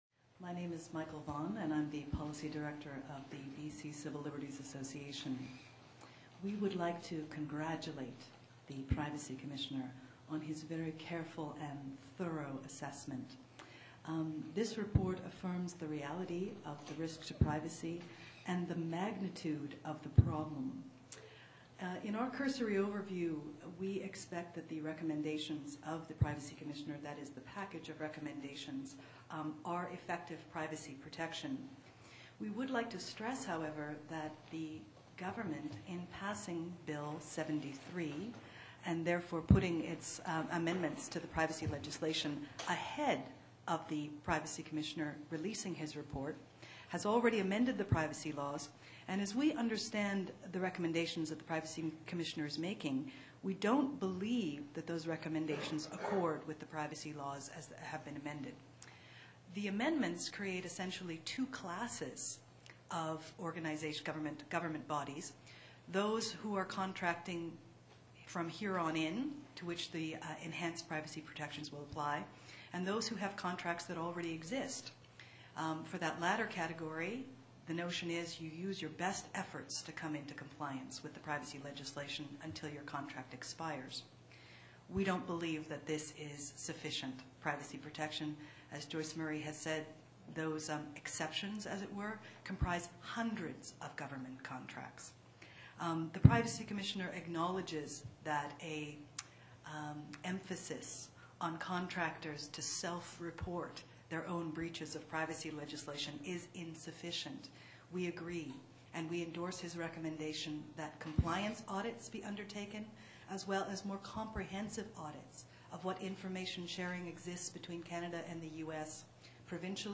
October 29, 2004 press conference by the Right to Privacy Campaign to respond to the BC privacy commissioners report
Press conference speakers